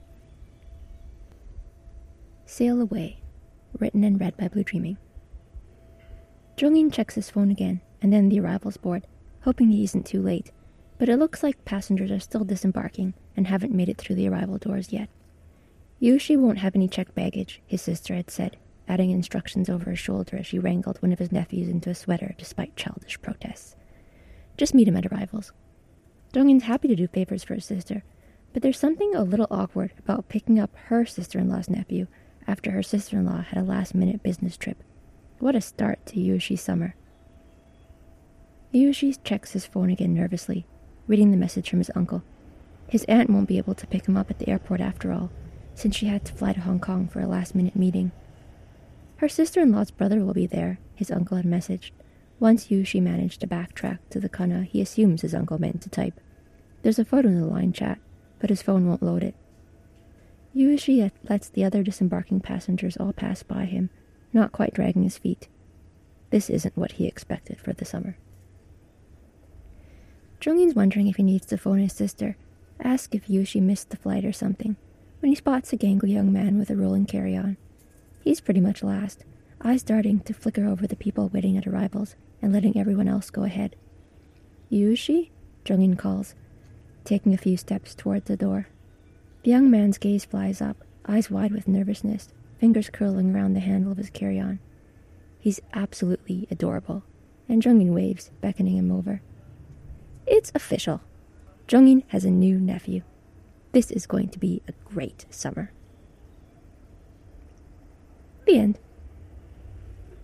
audio is a field recording